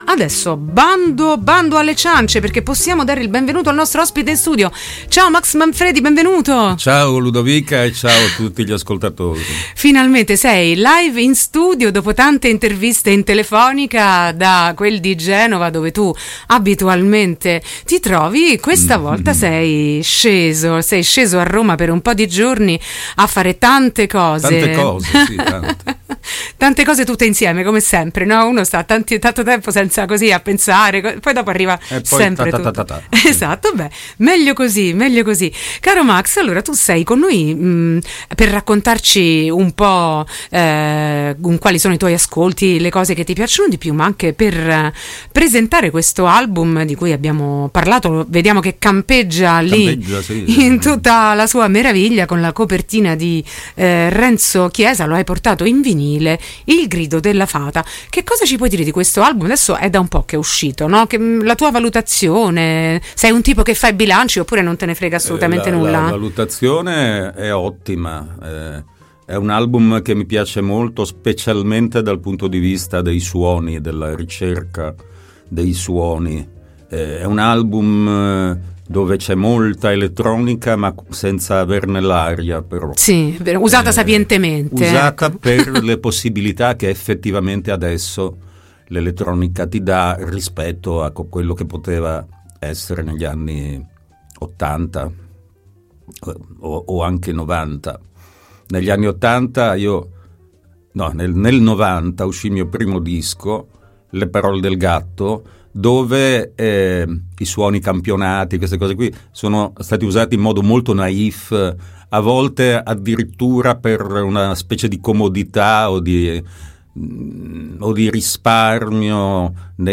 Intervista e minilive con Max Manfredi | Radio Città Aperta
Il cantautore genovese Max Manfredi nostro ospite in studio ha presentato agli ascoltatori il proprio ricchissimo percorso artistico oltre a una sua personale scelta di brani musicali.
intervista-max-manfredi-19-1-24.mp3